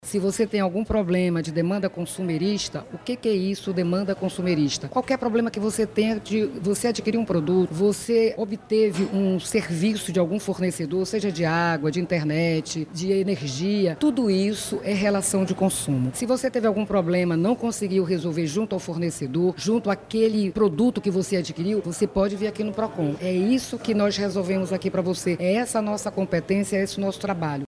Onilda Abreu, presidente do Procon Manaus, explica quais os serviços que podem ser acessados na unidade da galeria Espírito Santo.